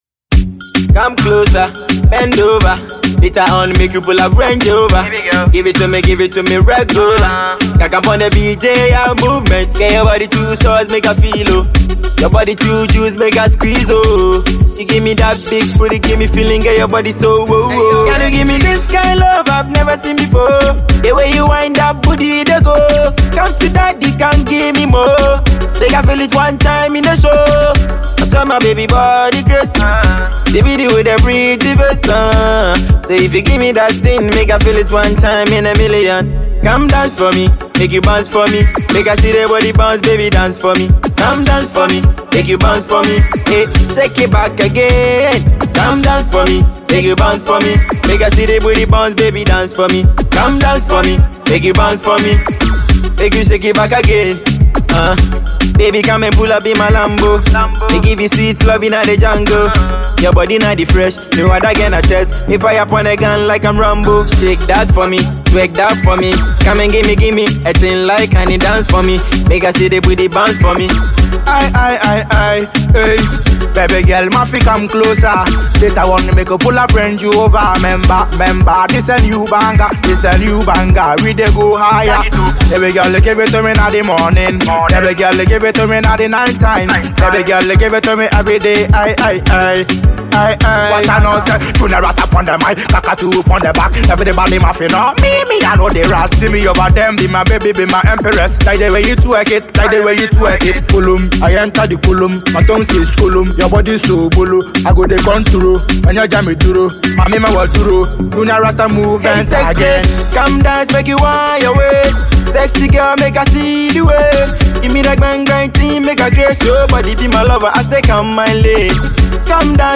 dancehall
reggae